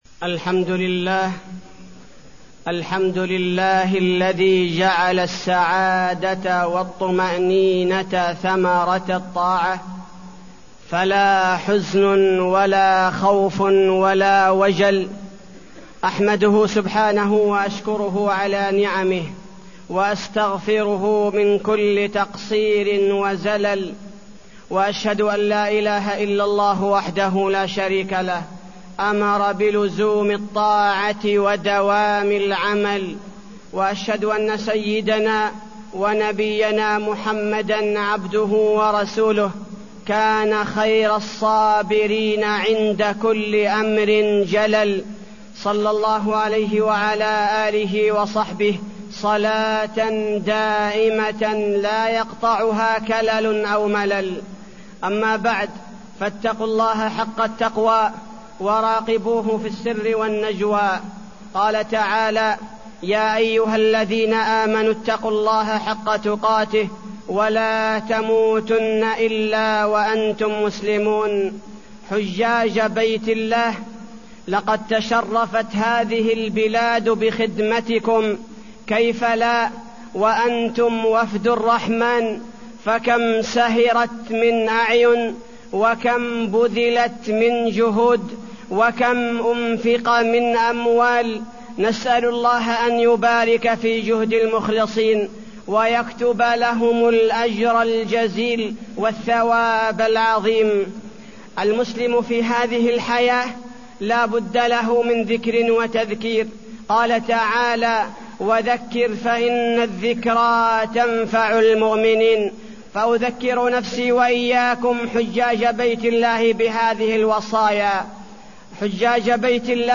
تاريخ النشر ١٨ ذو الحجة ١٤٢٠ هـ المكان: المسجد النبوي الشيخ: فضيلة الشيخ عبدالباري الثبيتي فضيلة الشيخ عبدالباري الثبيتي نصائح للحجاج